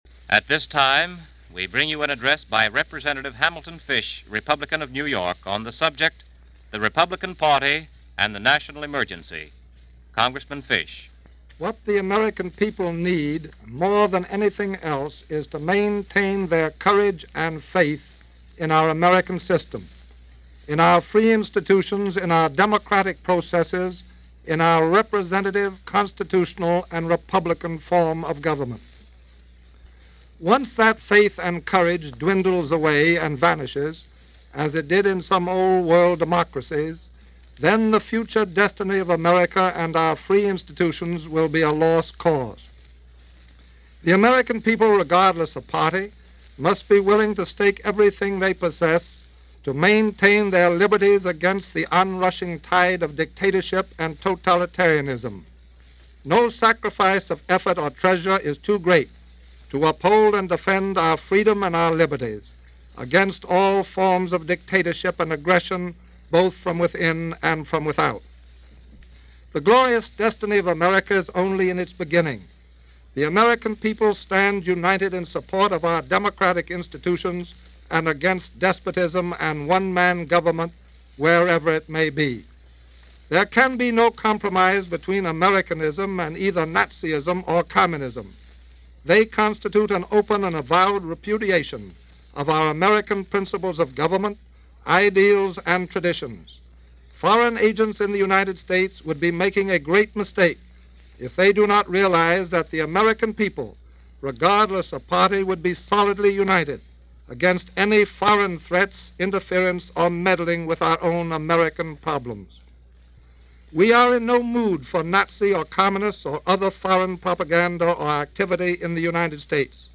In this 1940 broadcast address, Hamilton Fish makes his continuing case against our involvement in the European War – in 1940 there was still a strong case against it, but as time went on, news reports and German conquests were making our isolation less and less feasible. Here is that broadcast, given by Congressman Hamilton Fish on November 23, 1940.